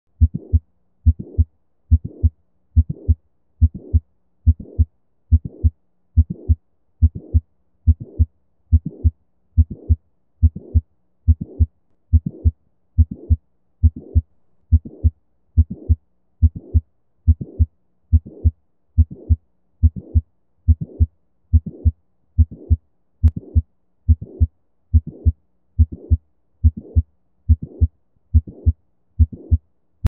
Mitral Valve Prolapse is described as a mid-systolic click usually accompanied by a late systolic murmur.